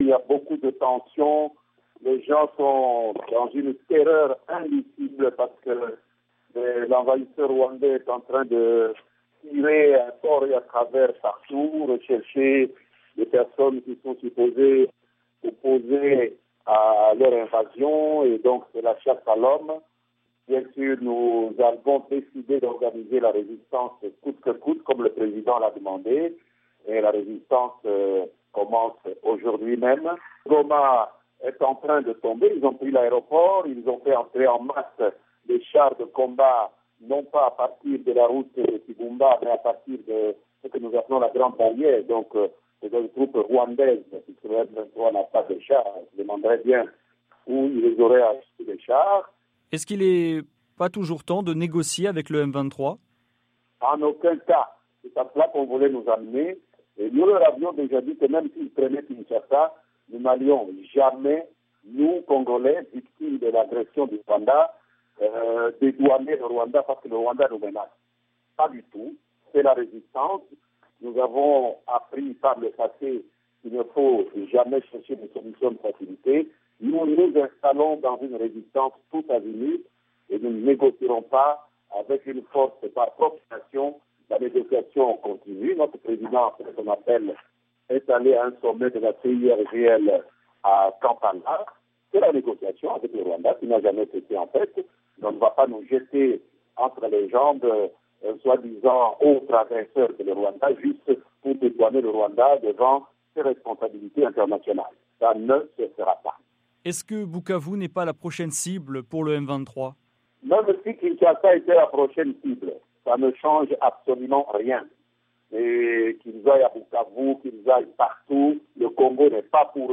Lambert Mende, porte-parole du gouvernement congolais